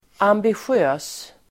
Ladda ner uttalet
ambitiös adjektiv, ambitious Uttal: [ambisj'ö:s]